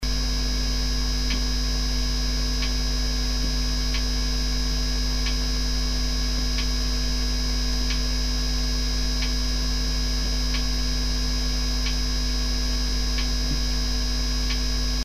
Voici mon buzz en Mp3.
Cela ressemble à un parasite radio-électrique auquel l'ampli serait sensible.
Le bruit que l'on entend est tout à fait le bruit caractéristique d'une clôture électrique.
buzzz.mp3